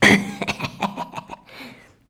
LAUGHTER.wav